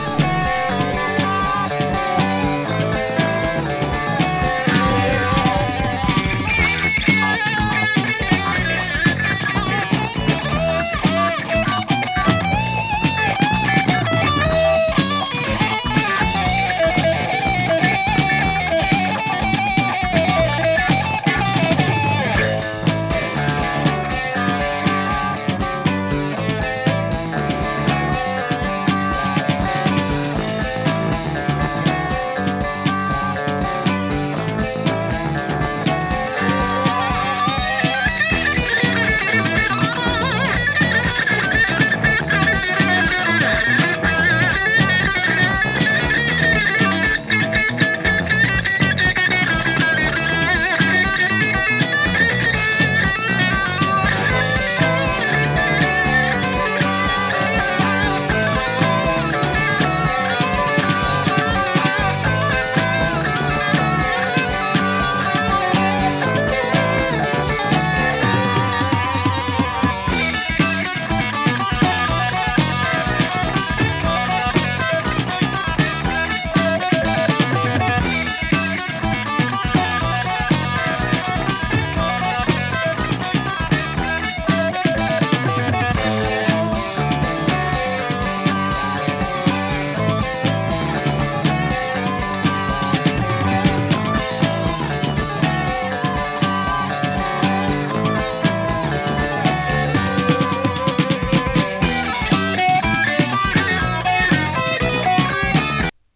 緩急をつけた組曲形式がドラマチックでスリリング、インスト主体でジャズロック・テイストも感じさせる演奏に、
お互いの手の内を知り尽くしたような二人のギターの絡みが絶妙なプログレ・ハードです！